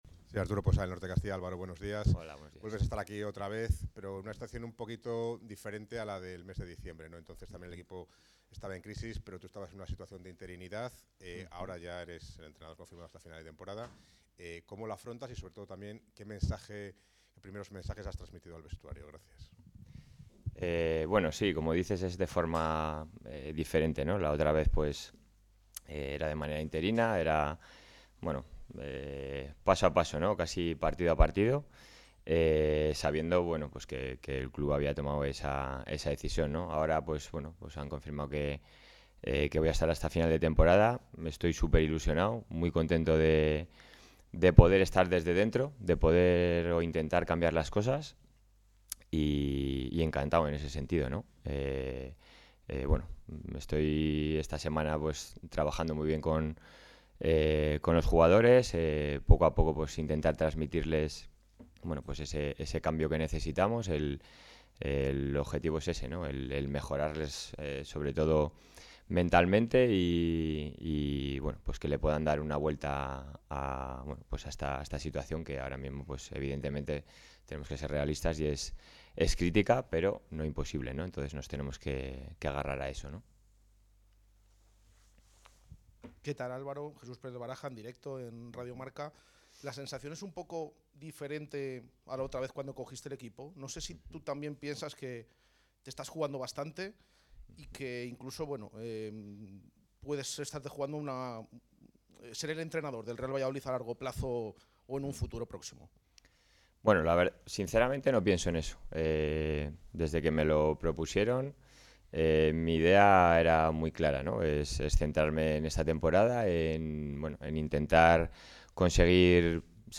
Con esas palabras asumió Álvaro Rubio la rueda de prensa previa al duelo de este domingo ante el Athletic Club en San Mamés en el que el Real Valladolid va a buscar la victoria con equilibrio en defensa y sin renunciar al ataque.